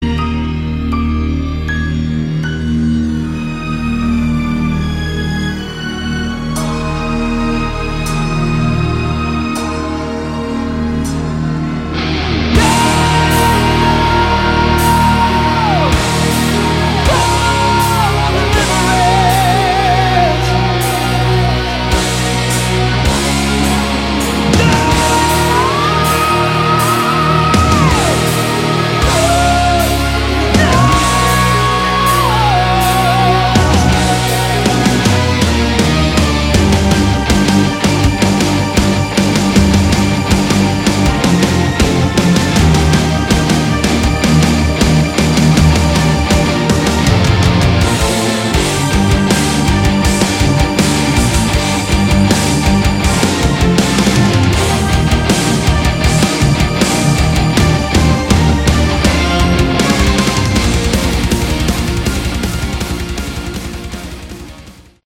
Category: Prog Rock / Melodic Metal
bass
guitar
vocals
keyboards
drums